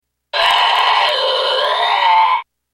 Chaque bouchon a une voix enregistrée qui lui est associée, cliquez sur le nom du bouchon pour l'écouter.